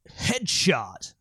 Voices / Male
Headshot.wav